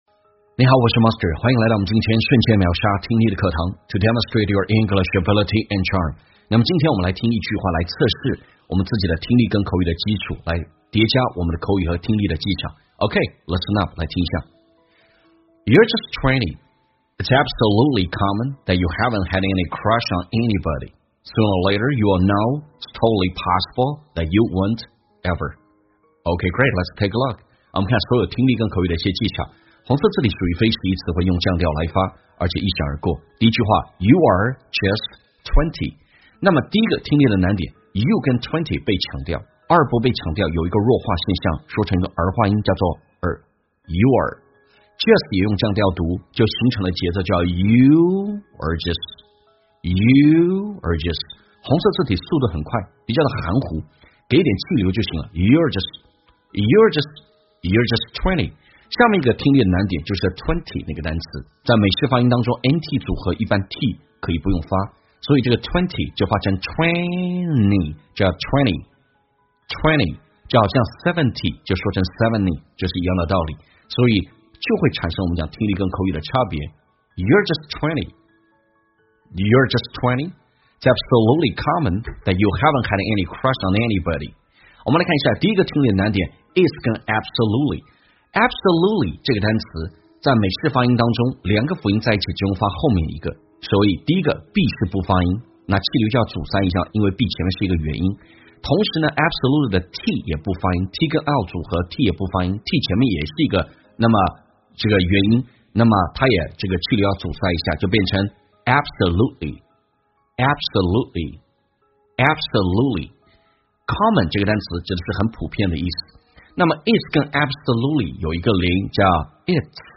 在线英语听力室瞬间秒杀听力 第359期:你才二十多岁的听力文件下载,栏目通过对几个小短句的断句停顿、语音语调连读分析，帮你掌握地道英语的发音特点，让你的朗读更流畅自然。